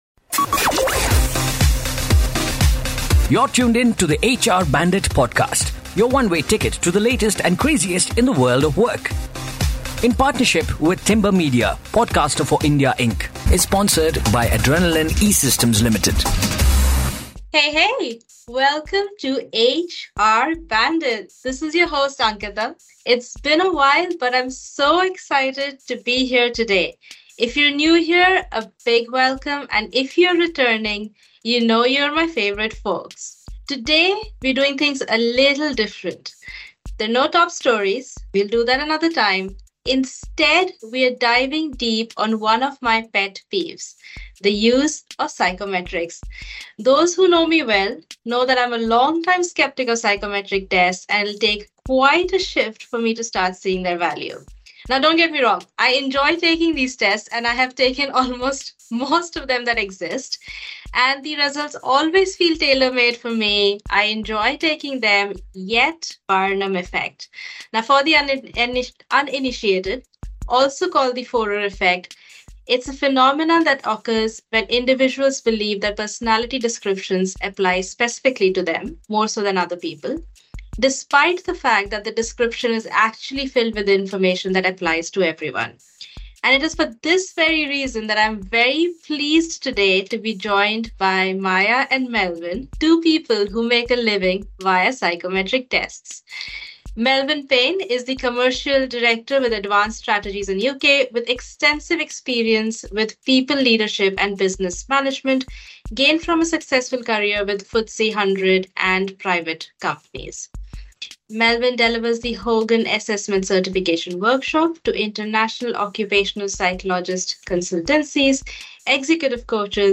two fellow HR professionals who make a living off psychometric tests as they work to convert me into a believer.